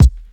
Tuned kick drum samples Free sound effects and audio clips
• '90s Mid-Range Kickdrum G# Key 287.wav
Royality free kick sample tuned to the G# note. Loudest frequency: 274Hz
90s-mid-range-kickdrum-g-sharp-key-287-Cbo.wav